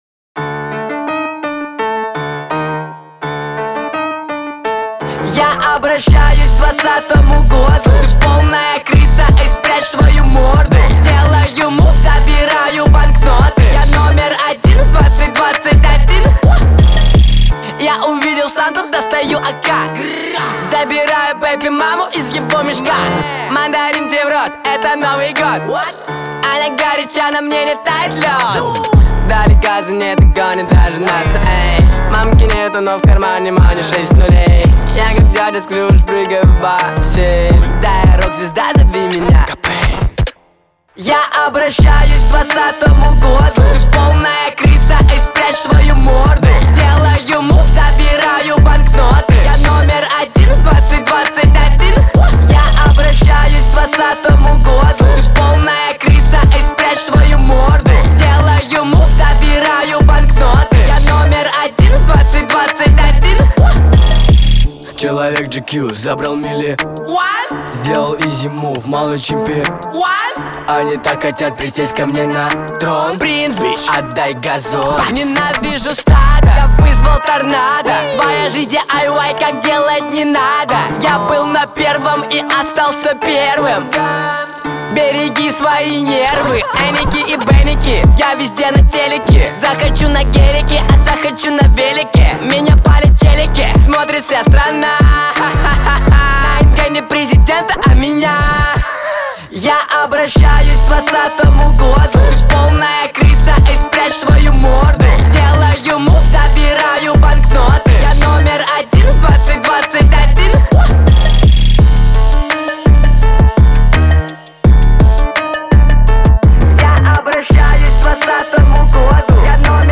в жанре поп